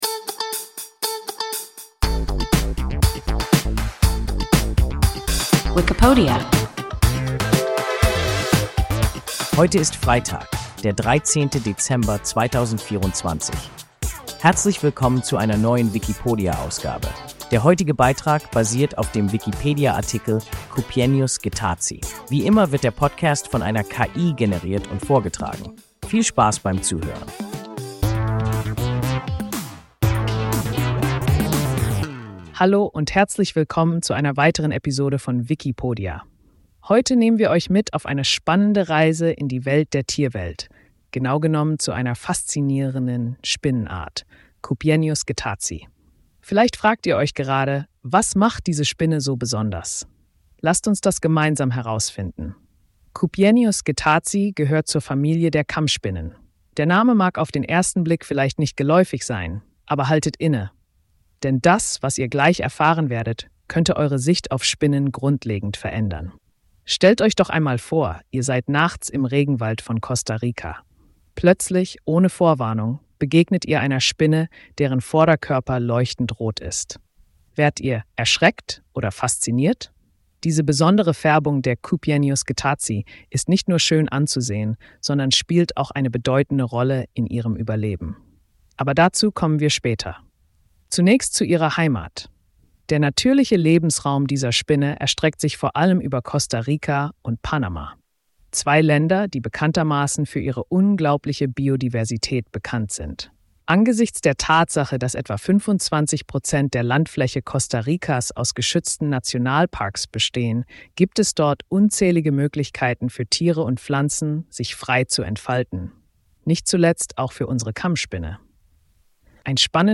Cupiennius getazi – WIKIPODIA – ein KI Podcast